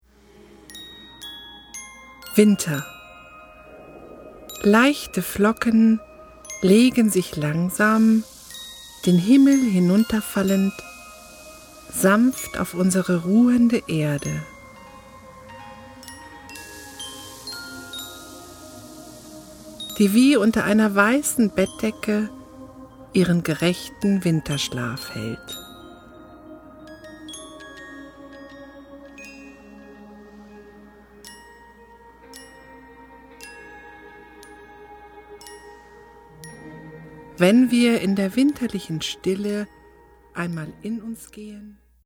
SyncSouls Natur-Meditationen Vol. 1 - geführte Meditation
Wir laden Sie ein auf eine meditative Reise mit einfühlsamen Texten und wunderschöner Musik durch die vier Jahreszeiten!